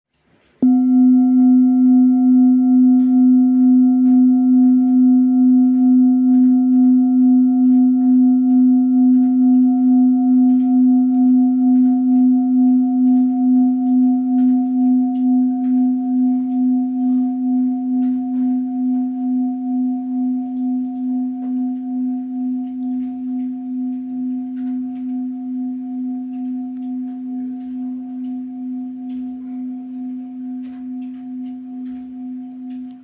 Full Moon Bowl, Buddhist Hand Beaten, Moon Carved, Antique Finishing, Select Accessories
Material Seven Bronze Metal
This is a Himalayas handmade full moon singing bowl.